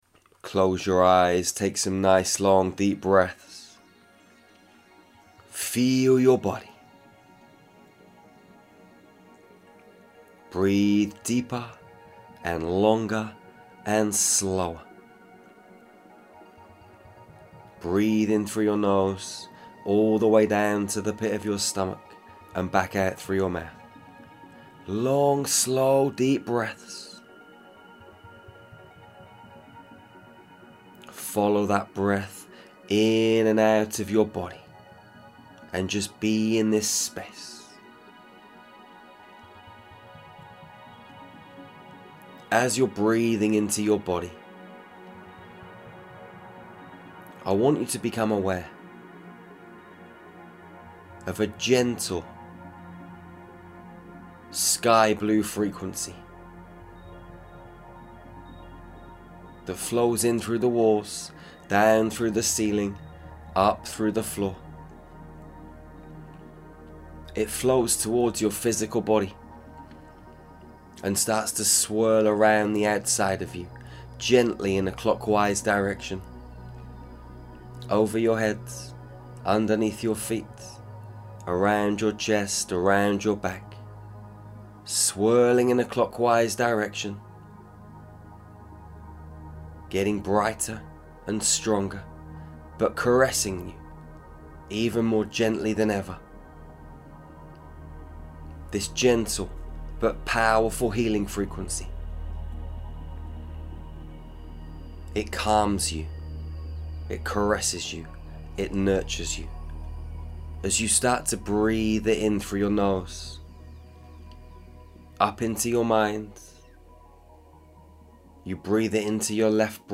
This meditation will take you into a space of peace and calmness. Any heaviness, worry, fear, will simply slide off from and out from your body, it will disappear from your consciousness, as it transmutes into positive energy and pure white light.